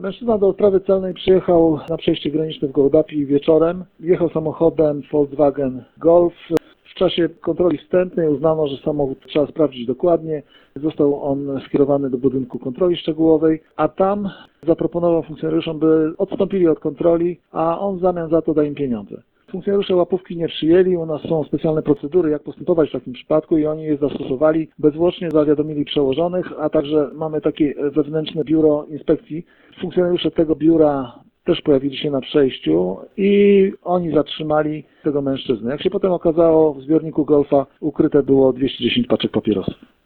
Mówi podinspektor